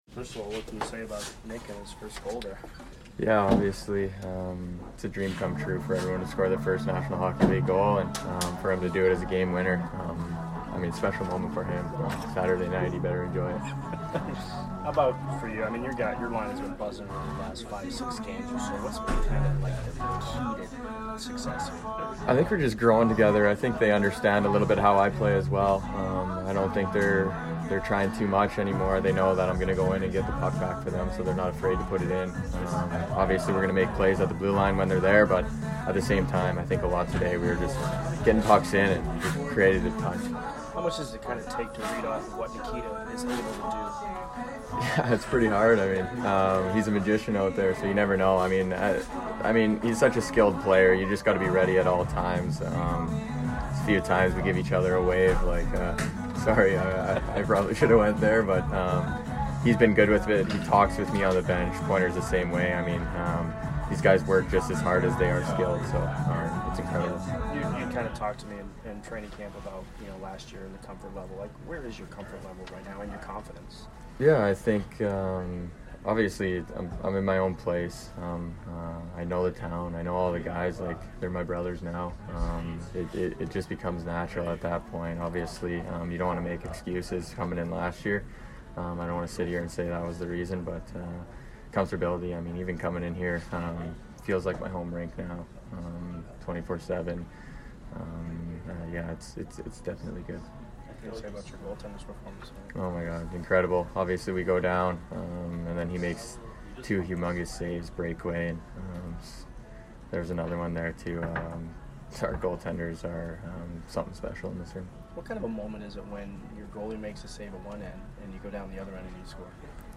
Brandon Hagel Post Game 11/5/22 vs BUF